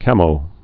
(kămō)